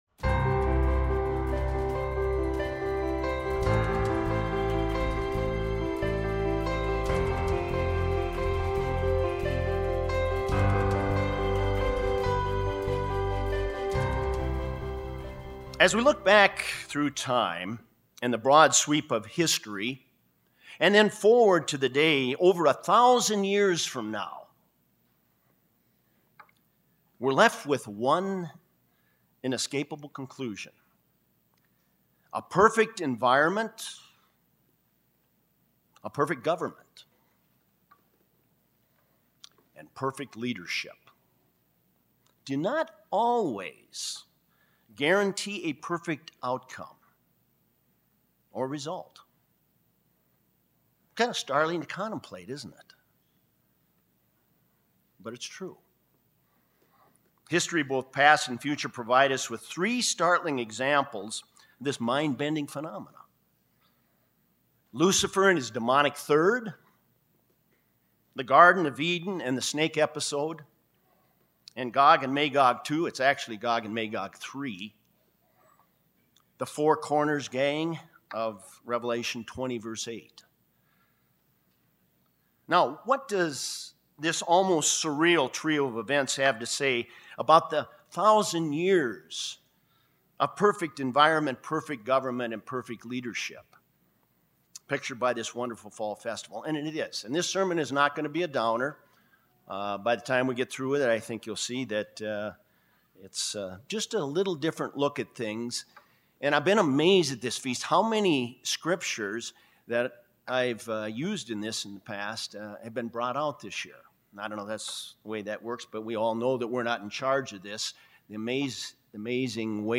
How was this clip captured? This sermon was given at the Lake Junaluska, North Carolina 2017 Feast site.